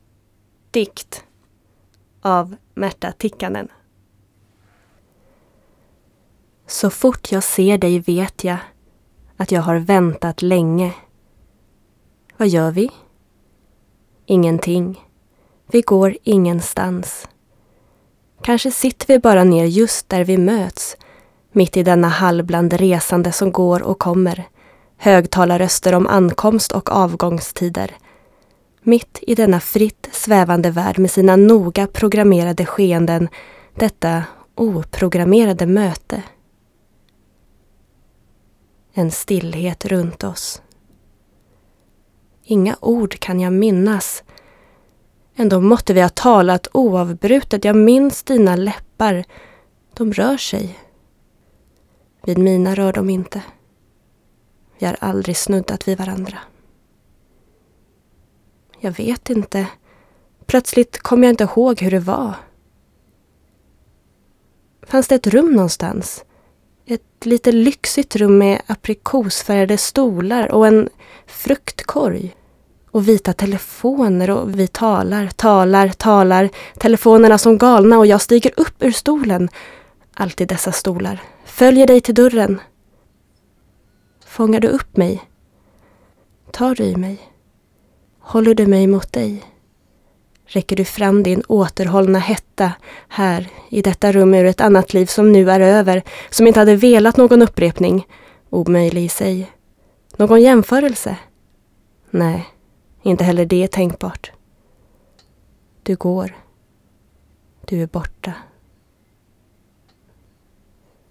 voice over demo (swedish)
poetry (in swedish)